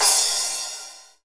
SCRASH HI.wav